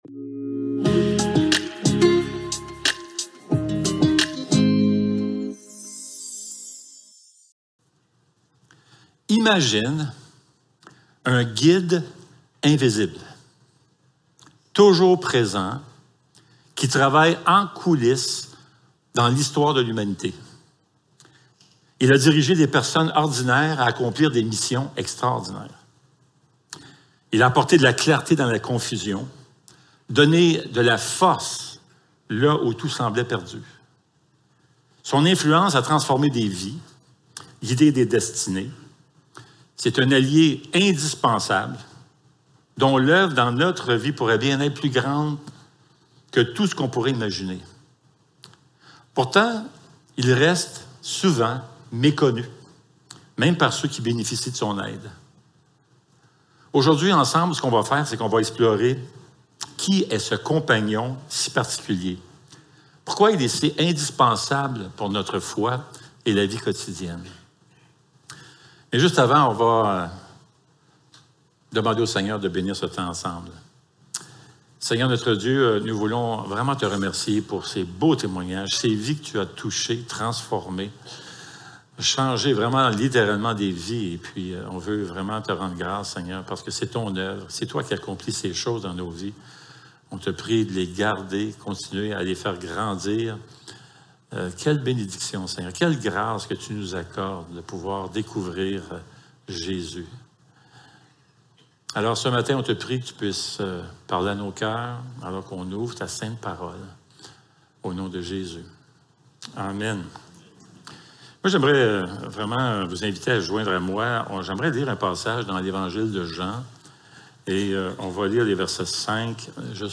Jean 16.7-15 Service Type: Célébration dimanche matin Description